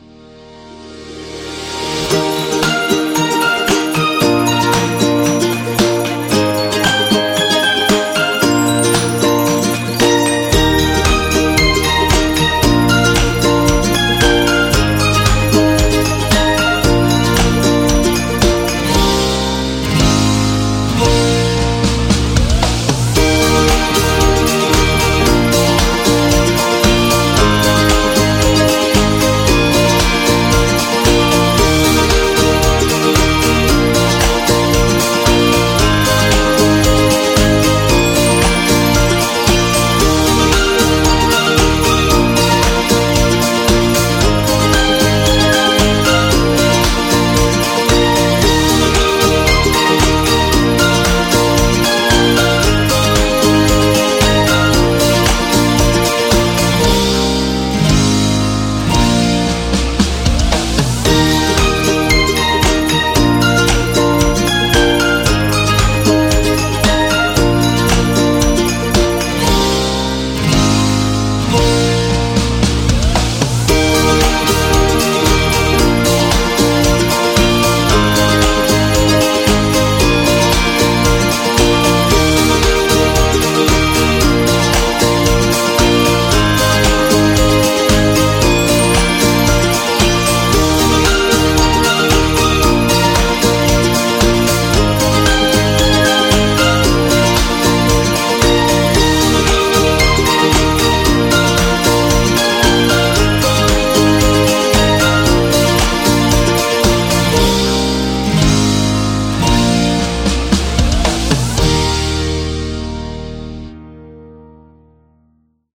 Uplifting, playful, and heartwarming